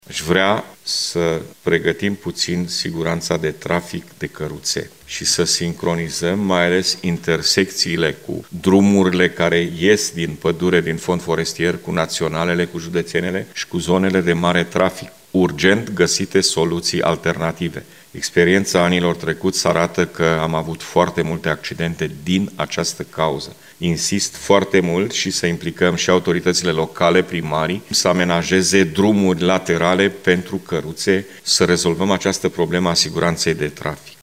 În cadrul ședinței legislativului județean, el a spus că trebuie găsite soluţii pentru rezolvarea problemei.